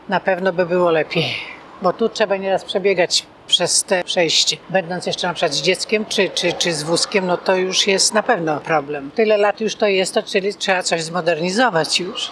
Kobieta zwraca także uwagę na to, że zielone światło na przejściu dla pieszych na ul. Wojska Polskiego, na wysokości Ełckiego Centrum Kultury, świeci się zbyt krótko.